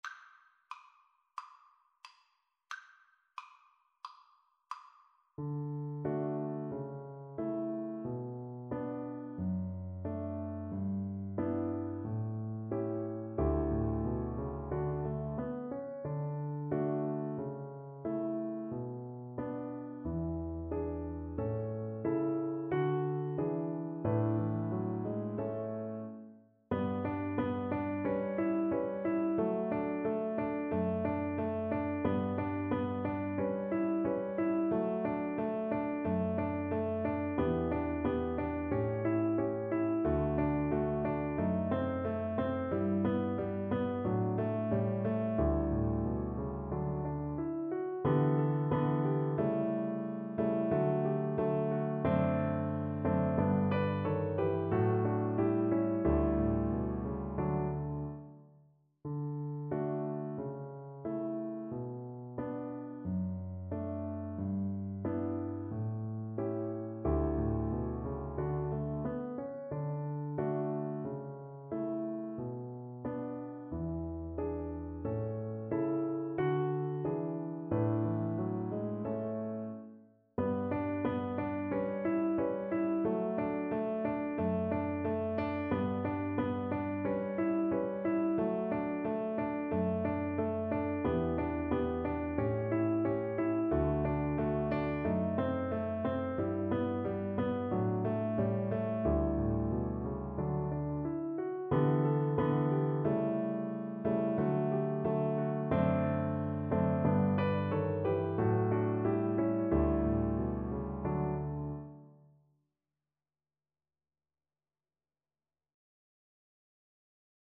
Classical Schubert, Franz An den Mond D.259 Cello version
Cello
2/2 (View more 2/2 Music)
D major (Sounding Pitch) (View more D major Music for Cello )
= 45 Ziemlich langsam
Classical (View more Classical Cello Music)